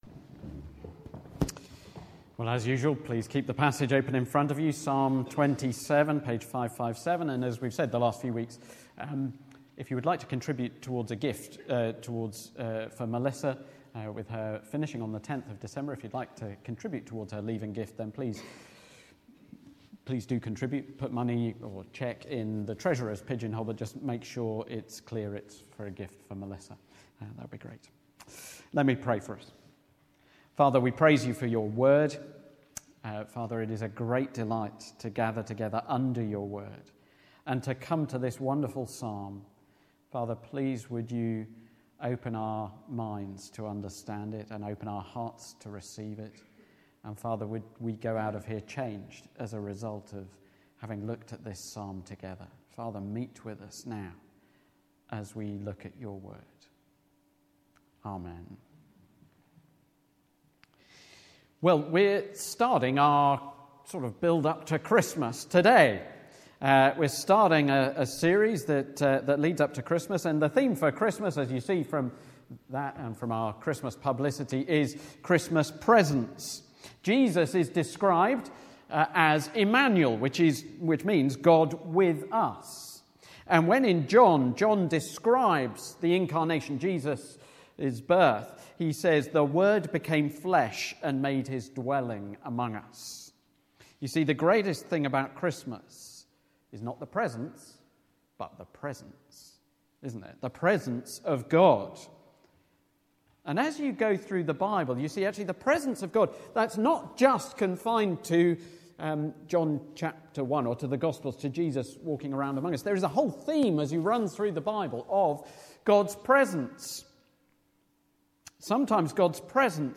Psalm 27 Service Type: Sunday Morning Topics